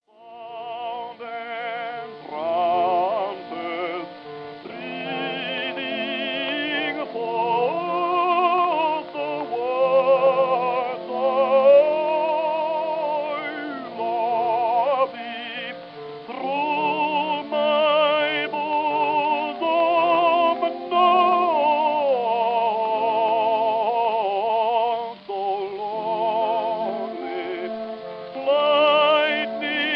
in recordings made in 1922 and 1927